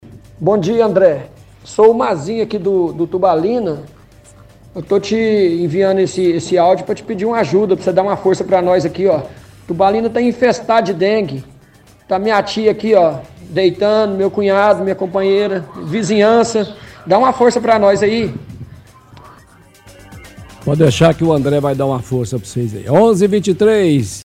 – Ouvinte reclama que o Tubalina está infestado de dengue*